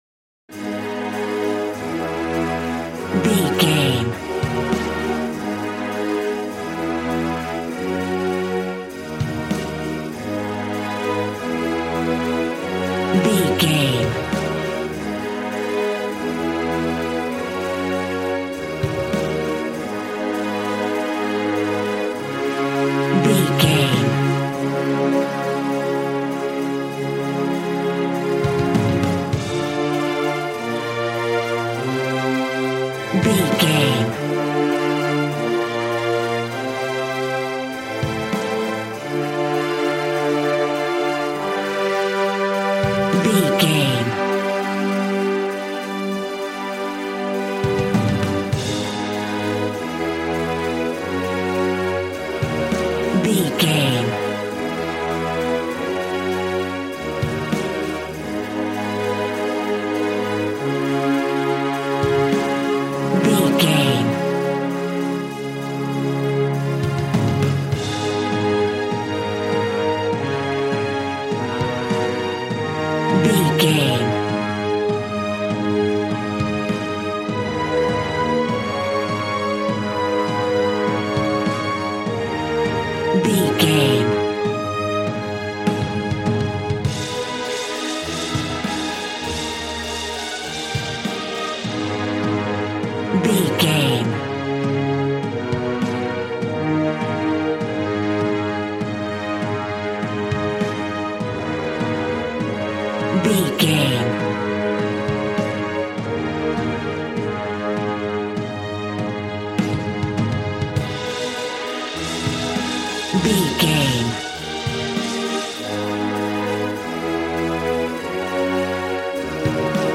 Aeolian/Minor
A♭
dramatic
strings
violin
brass